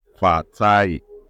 Captions English audio pronounciation of Fatai